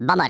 Worms speechbanks
bummer.wav